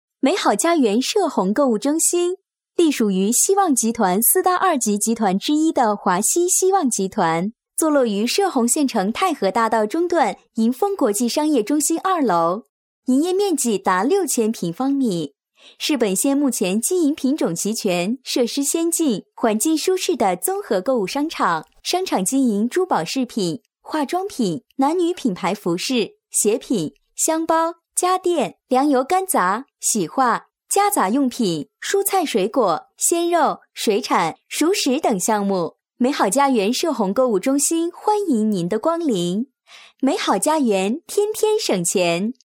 女声配音
亲切甜美